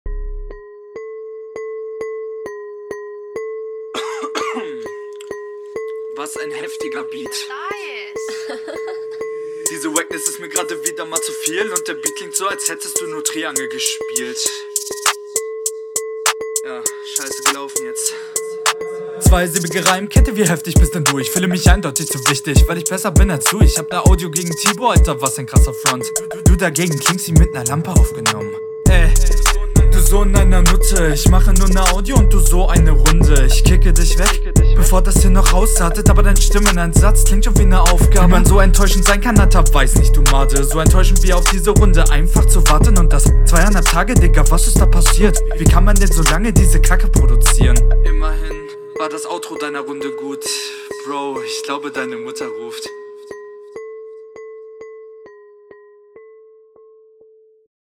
Flow: die double time Ansätze sind zum Feiern, der flow hat Variation und geht gut …
Flow: Er kommt sehr gut auf dem Beat. Er baut gute Variationen ein und gut …
Flow: Der flow ist ziemlich cool, aber bei 0:35 war ein leichter Flowfehler bzw verhasbler. …